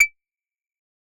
Perc 12.wav